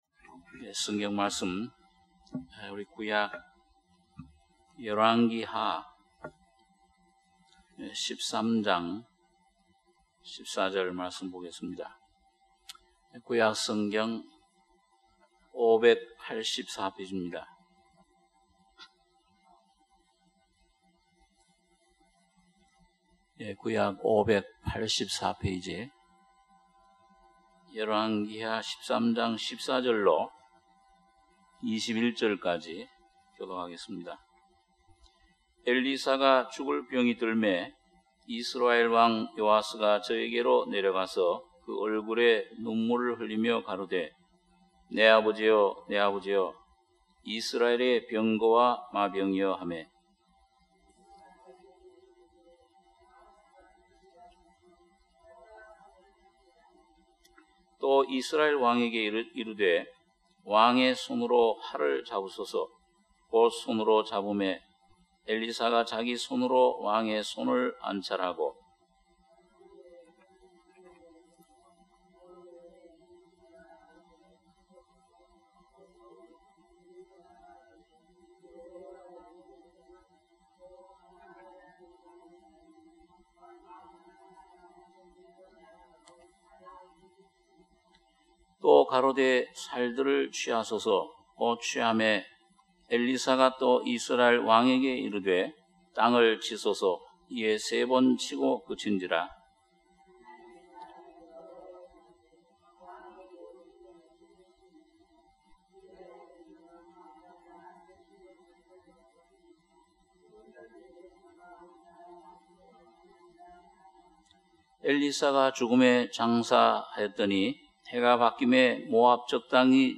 수요예배 - 열왕기하13장 14절~ 21절